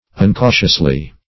uncautiously - definition of uncautiously - synonyms, pronunciation, spelling from Free Dictionary Search Result for " uncautiously" : The Collaborative International Dictionary of English v.0.48: Uncautiously \Un*cau"tious*ly\, adv.
uncautiously.mp3